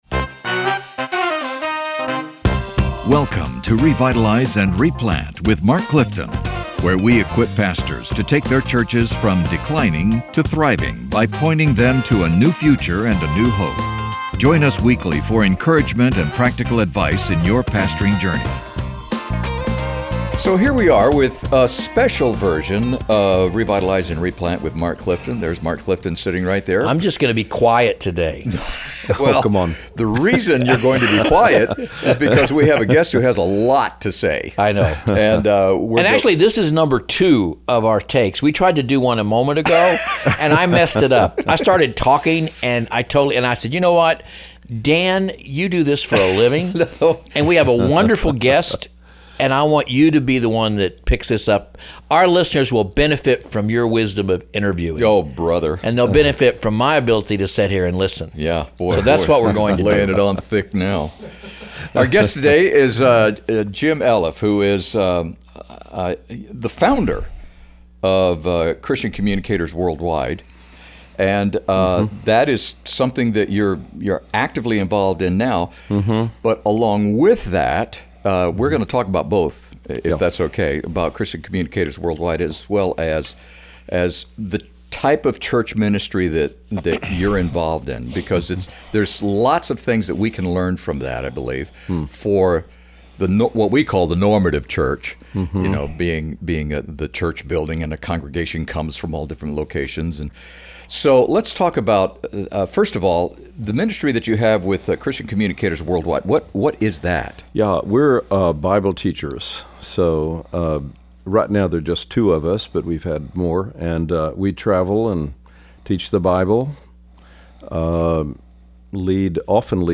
The Lord's Supper, Part 1 - A Conversation